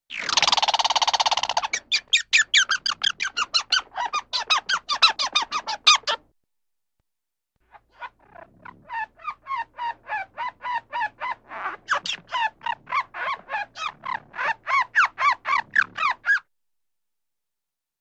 Звуки белки
Звук болтающей белки